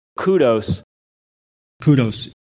Some give it the same final s as cosmos and Bezos: